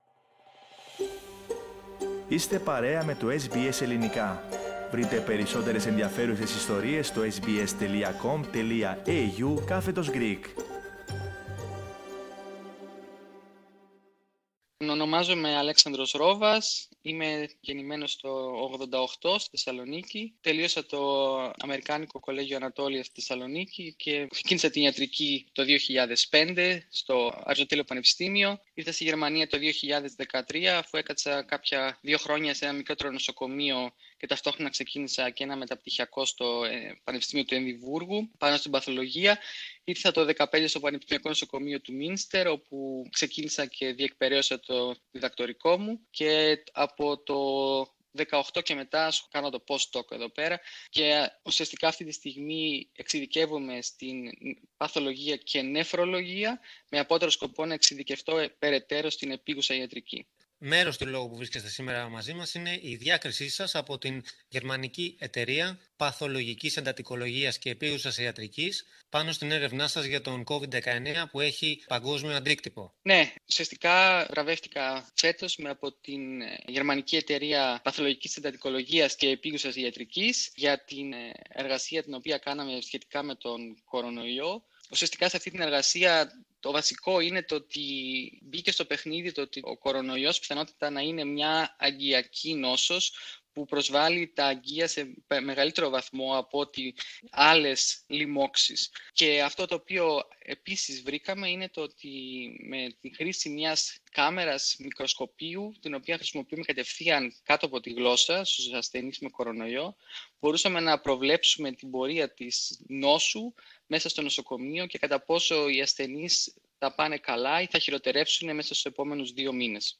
Στη συνέντευξη που ακολουθεί μιλά για τη βράβευσή του, την πανδημία, την ιατρική, την Ελλάδα αλλά και τι θεωρεί σημαντικό σε ότι φορά τη νόσο.